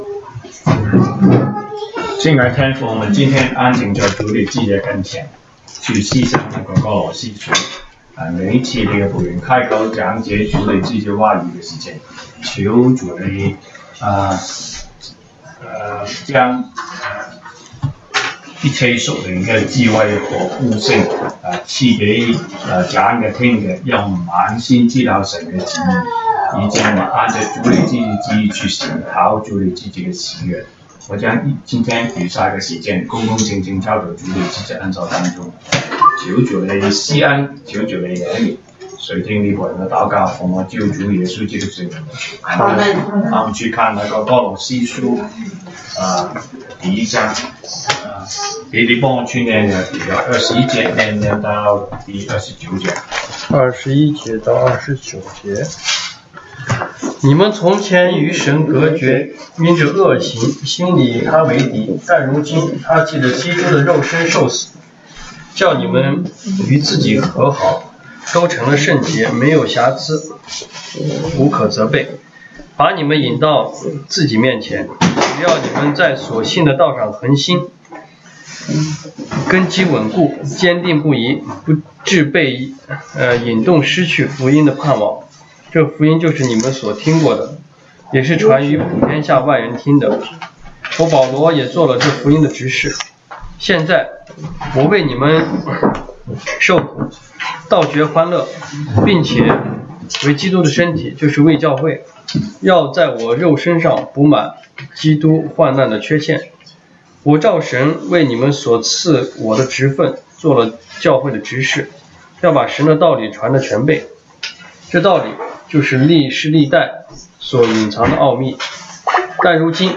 Service Type: 週一國語研經 Monday Bible Study